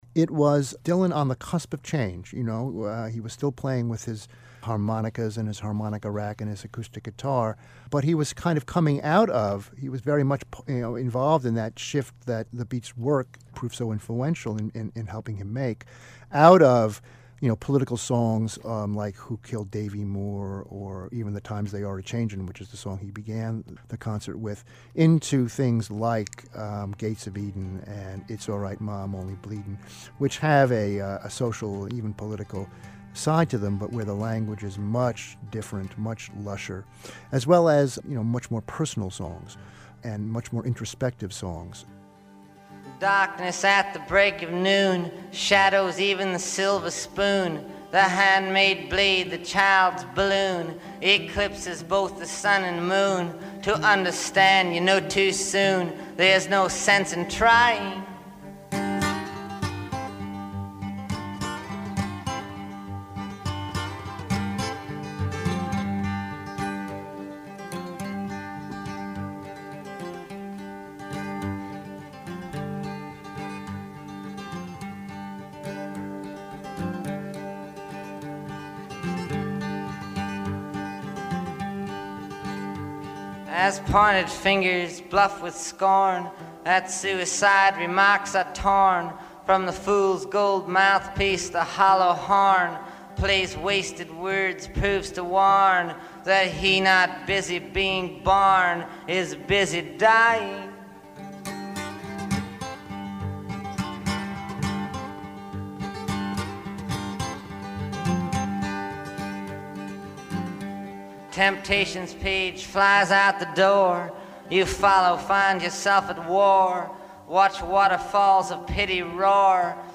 In this excerpt from the podcast, Wilentz describes the importance of Dylan’s 1964 concert at New York’s Philharmonic Hall, which Wilentz attended as a 13-year-old. [2:50]